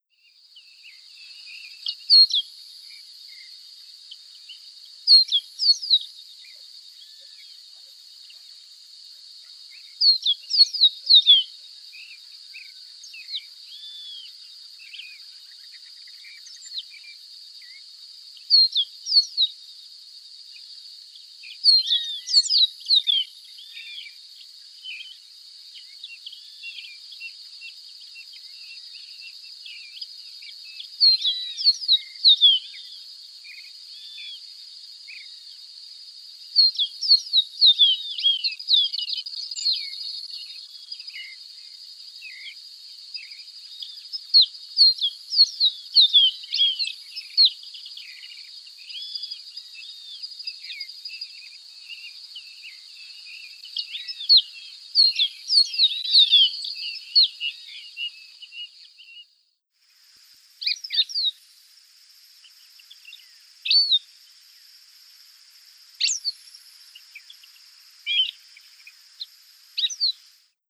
Sporophila bouvreuil pileata - Capuchino boina negra
Sporophila bouvreuil.wav